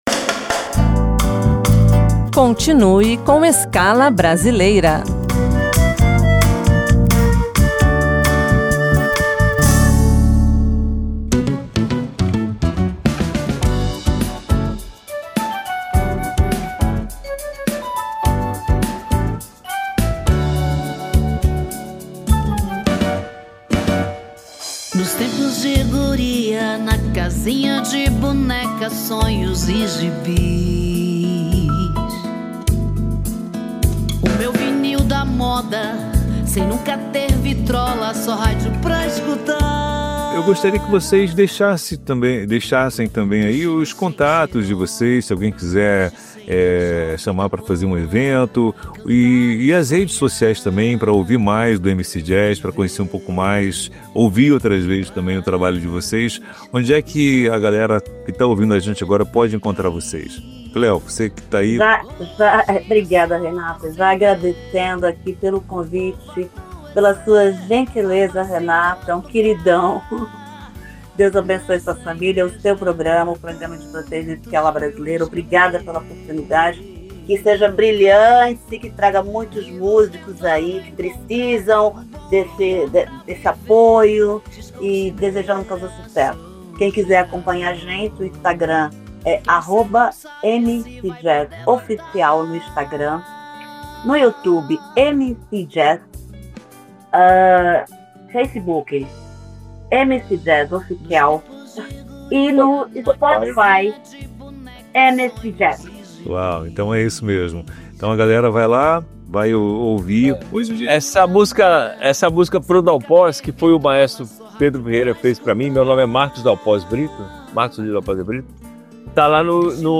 Nesta semana, apresentamos a segunda parte da entrevista com o grupo MC Jazz.